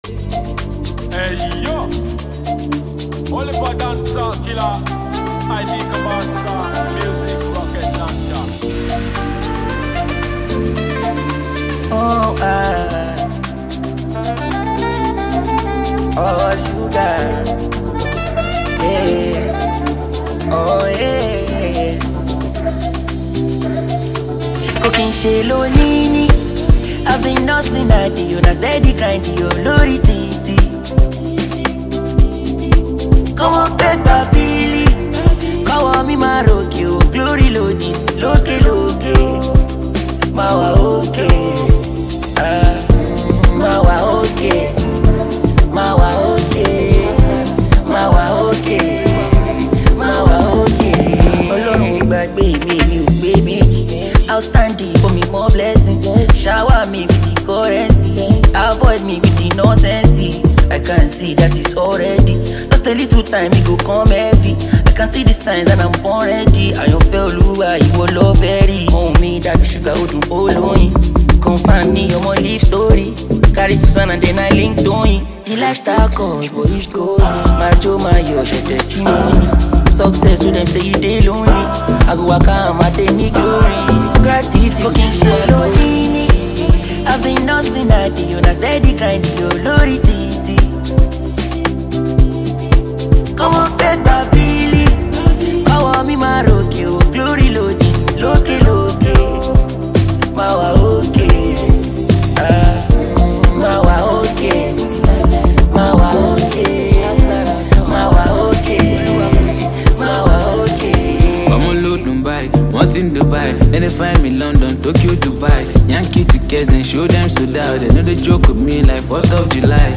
The latter's delivery here is, as always, top-notch.